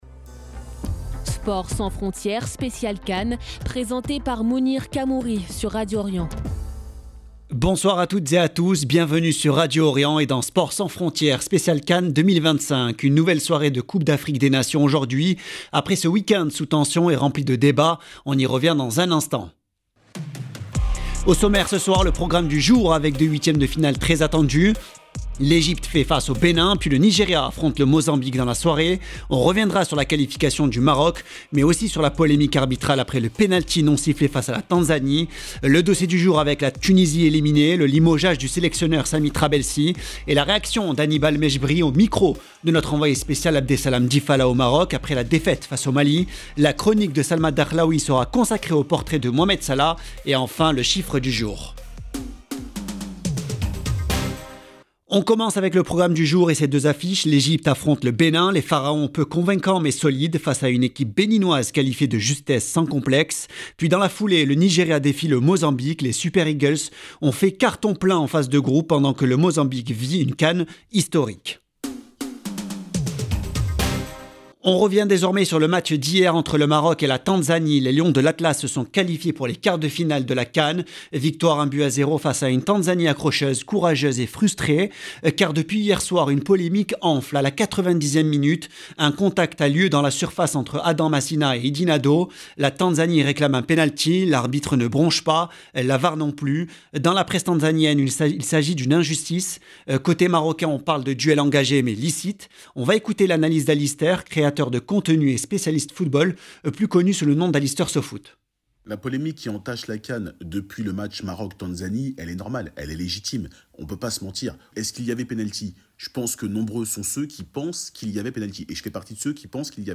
Sport sans frontières - émission spéciale pour la CAN